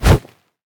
fixed kick sounds
fire1.ogg